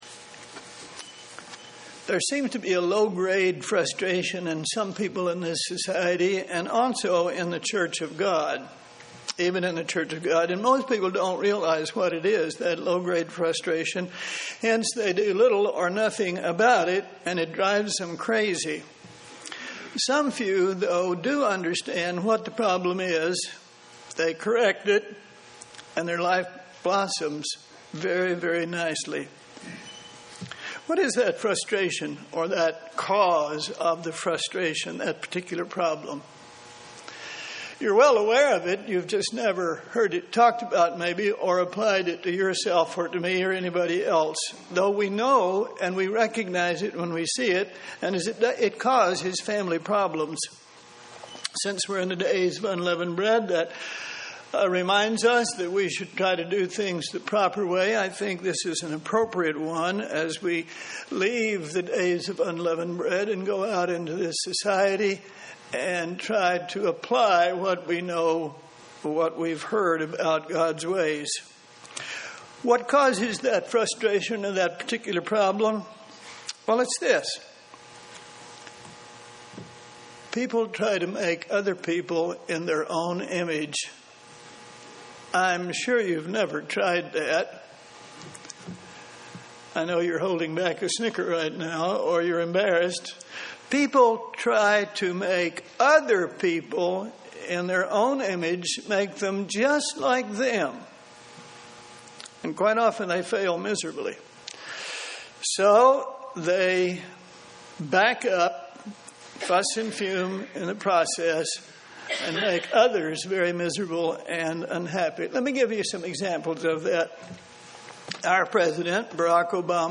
But as growing Christians, we must allow God to form us in His Image UCG Sermon Studying the bible?